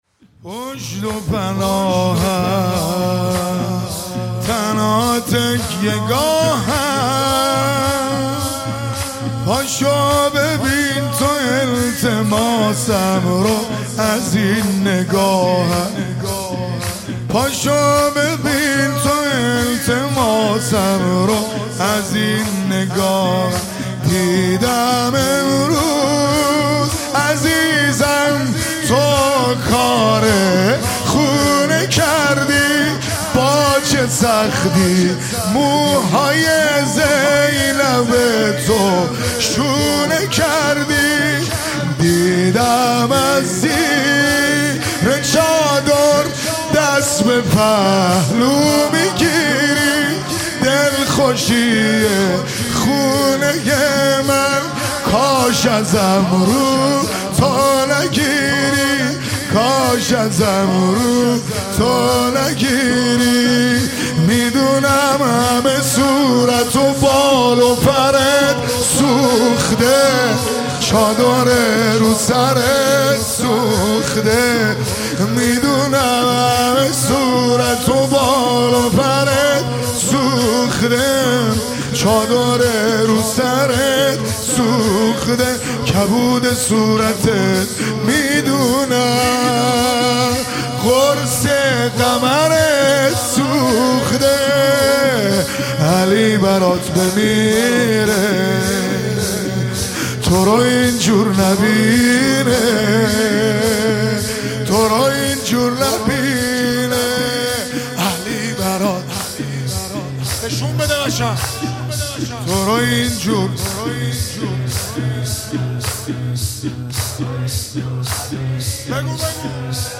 زمینه|پشت و پناهم
شب شهادت حضرت زهرا ۱۴۴۵